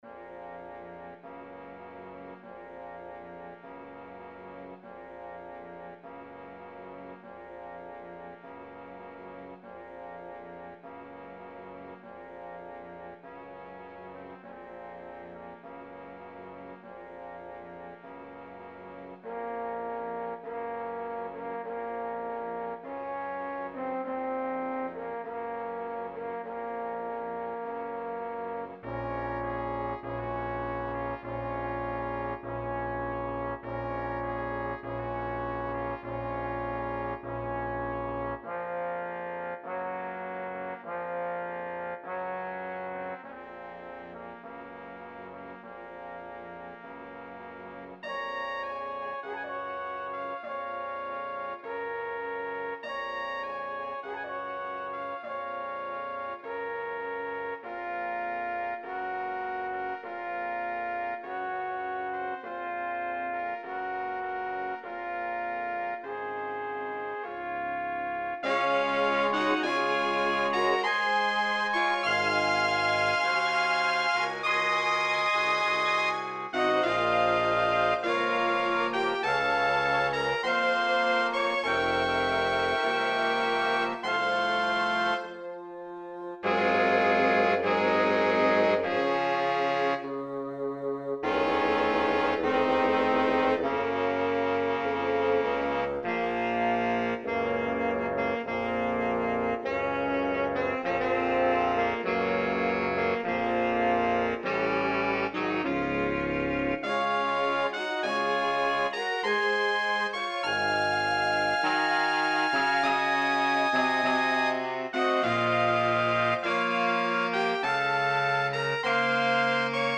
Lastjarcente Federiko Ŝopin' verkis tiun funebran kanton kadre de sia Dua Sonato por piano.
orĥestran version por la okazo